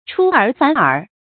注音：ㄔㄨ ㄦˇ ㄈㄢˇ ㄦˇ
出爾反爾的讀法